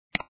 10155_click.mp3